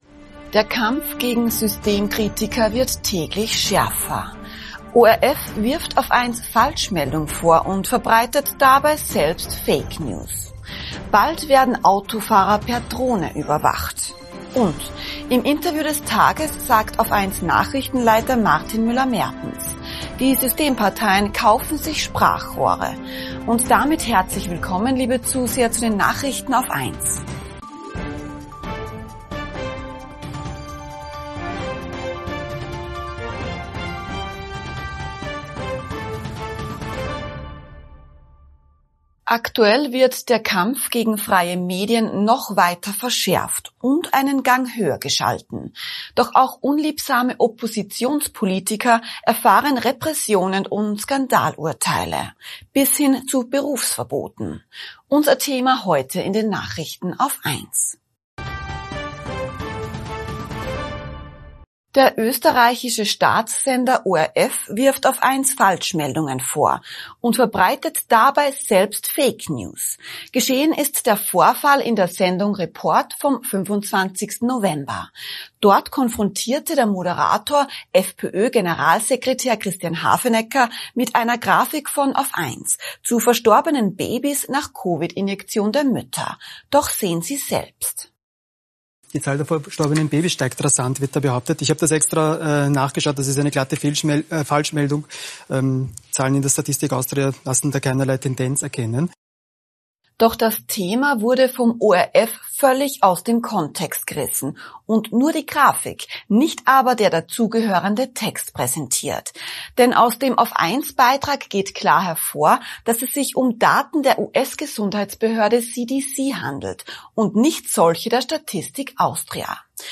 Nachrichten AUF1 vom 27. November 2025 ~ AUF1 Podcast
Interview des Tages